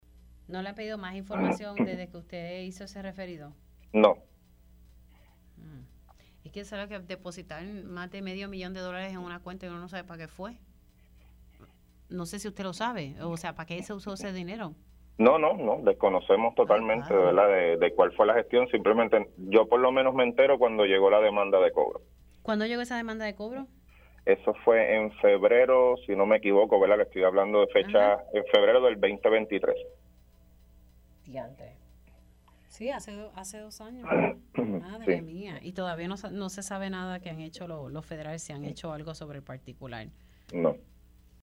El alcalde de Mayagüez, Jorge Ramos confirmó en Pega’os en la Mañana que refirieron a las autoridades federales los pagos irregulares de la Mayagüez Economic Development, Inc. (MEDI) a una cuenta bancaria en España.